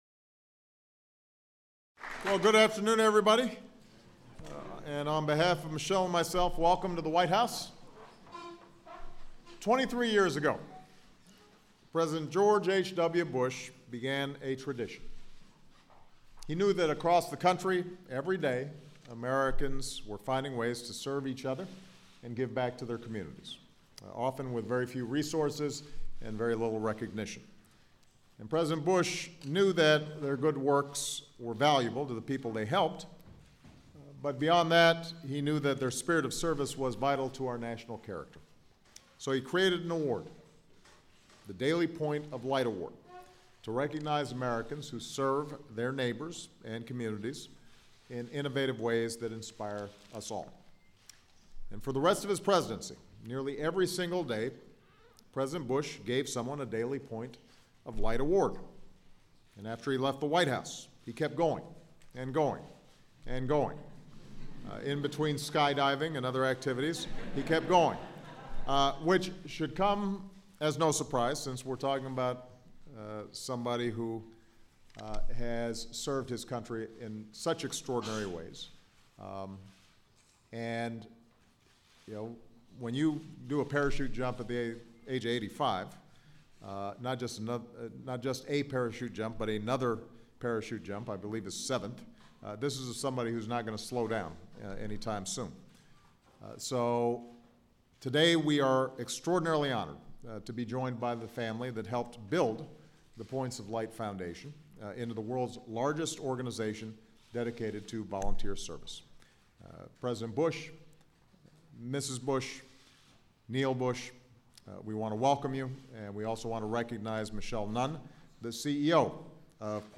U.S. President Barack Obama speaks at the Daily Point of Light Award ceremony
Held at the White House.